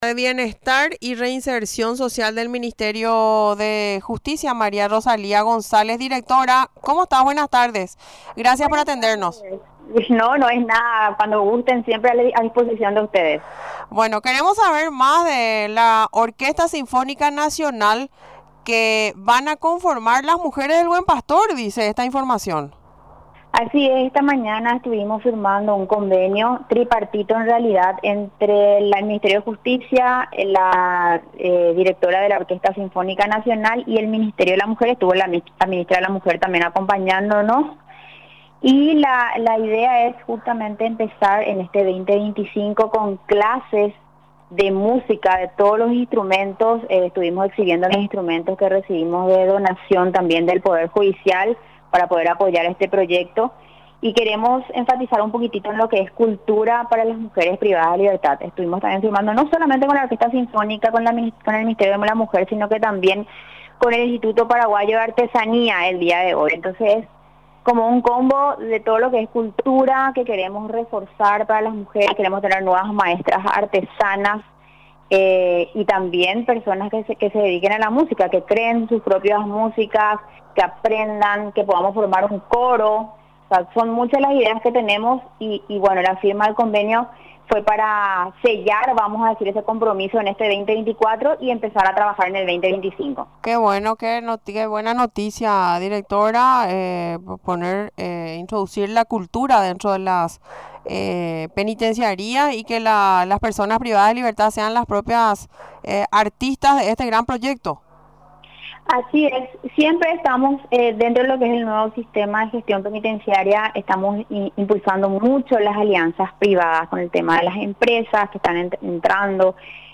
Durante la entrevista en Radio Nacional del Paraguay, explicó los detalles del convenio, principalmente lo relacionado al inicio de la tarea de conformar la Orquesta de Mujeres del Buen Pastor.